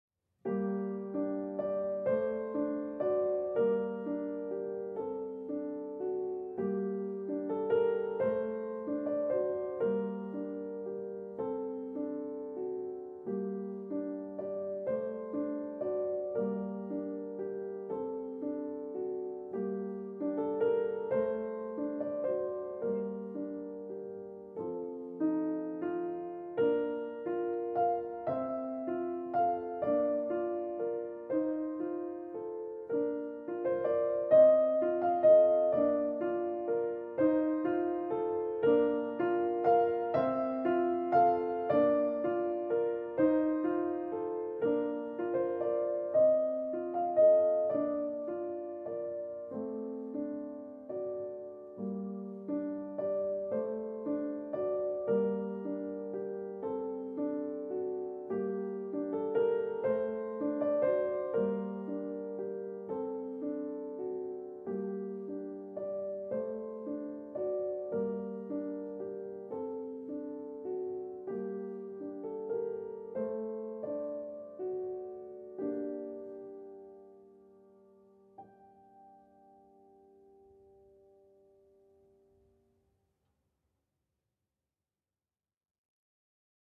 Music: Andantino 'Spring', B. 117 (looped)